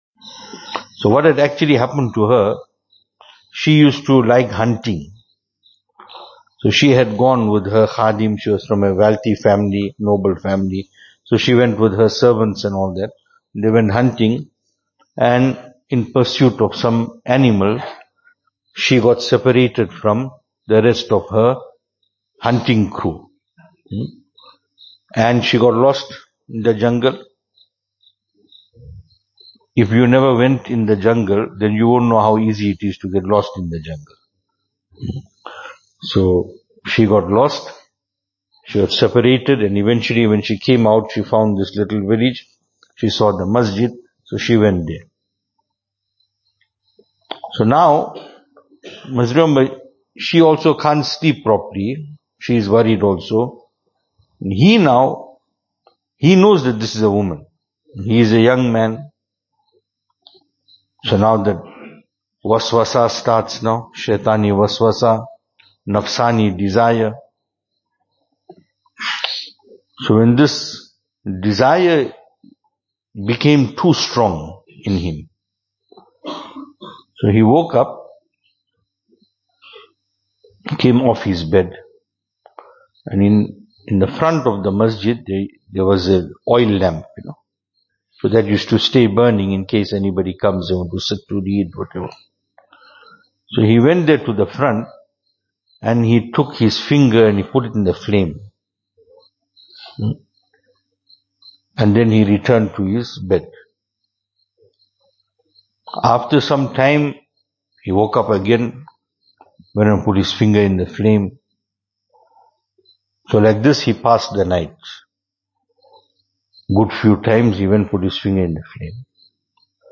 True friendship Venue: Albert Falls , Madressa Isha'atul Haq Series: Islahi Majlis Service Type: Islahi Majlis Topics: Islahi Majlis « The legal system of the kuffaar and the mindset of a believer .